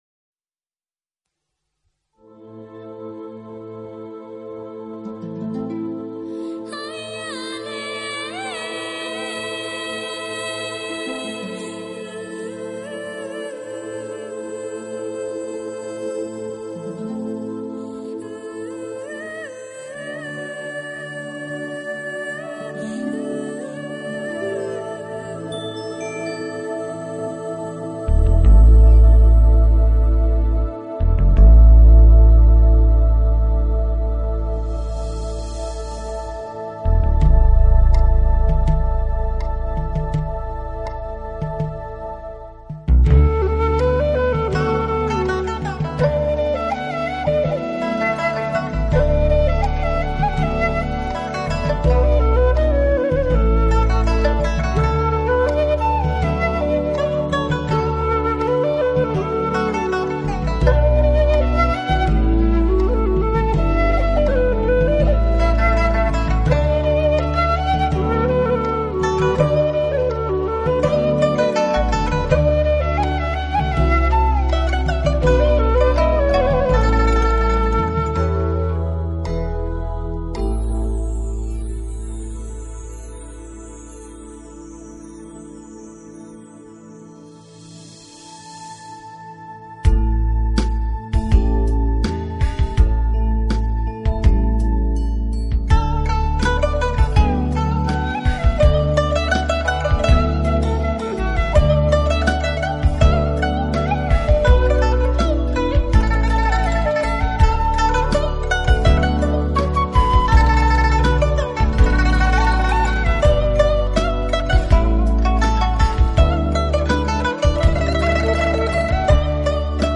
专辑歌手：纯音乐
江南丝竹，笙管笛箫琴筝琵琶，洞箫最具阴柔的品性。